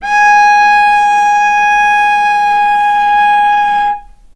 vc-G#5-mf.AIF